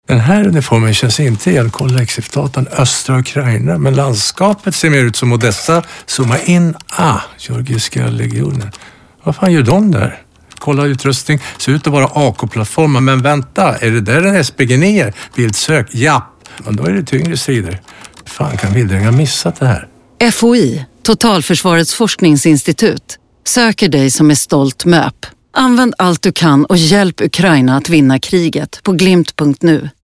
Radioreklam till möp, mp3